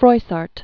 (froisärt, frwä-sär), Jean 1333?-1405?